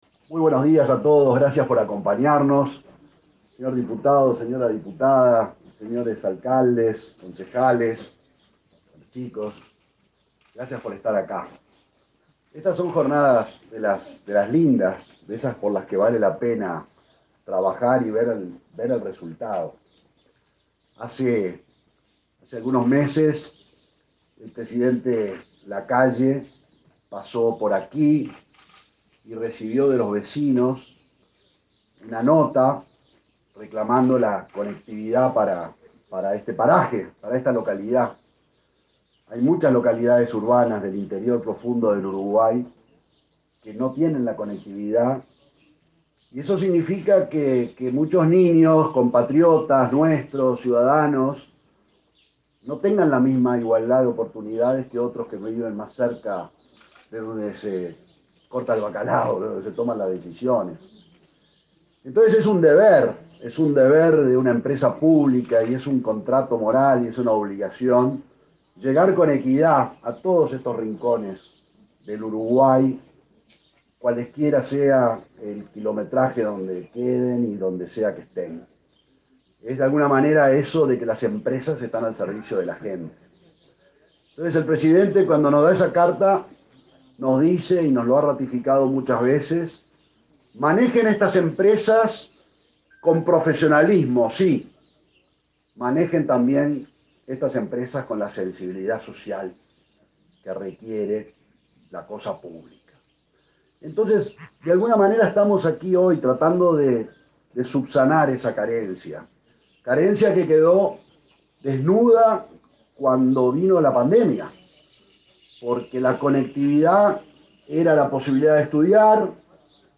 Palabras del presidente de Antel, Gabriel Gurméndez
La empresa Antel inauguró, este 12 de octubre, una radiobase en la escuela n.° 64, Luis Morquio, en Tala de Miguelete, departamento de Colonia.